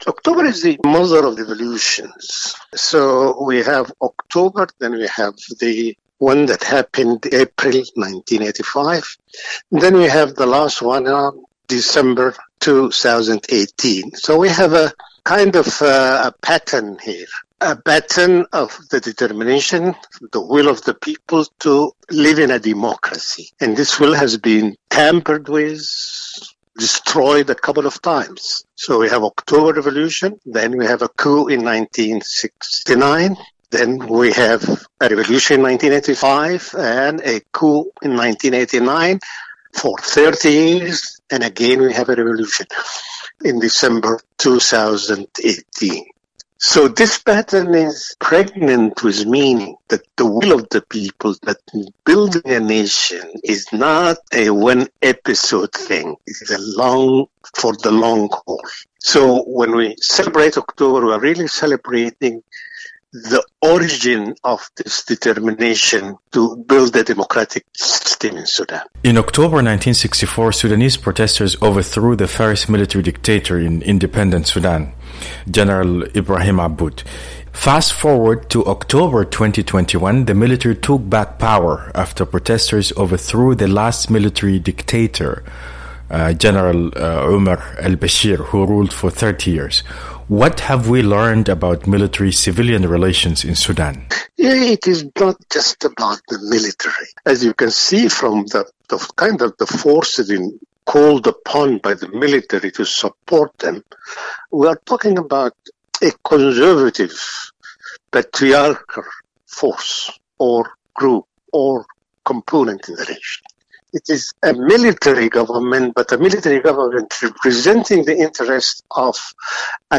The interview was edited for brevity and clarity.